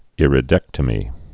(ĭrĭ-dĕktə-mē, īrĭ-)